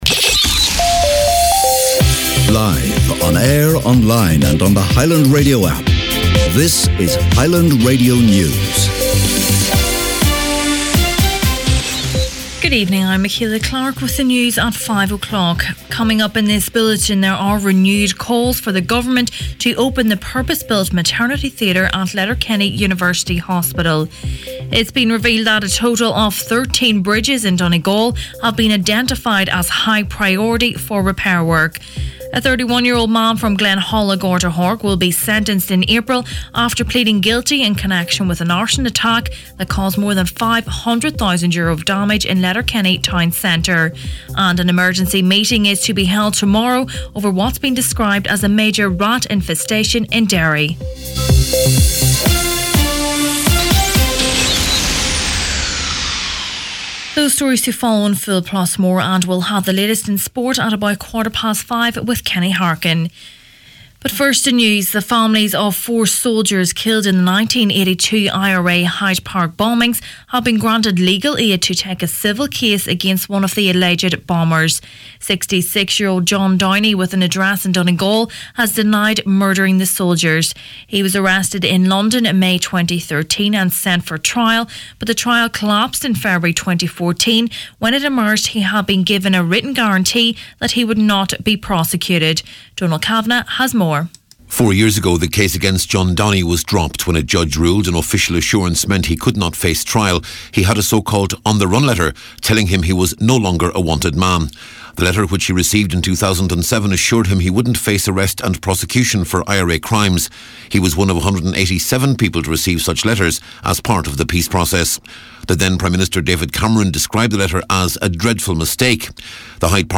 Main Evening News, Sport and Obituaries Tuesday 6th February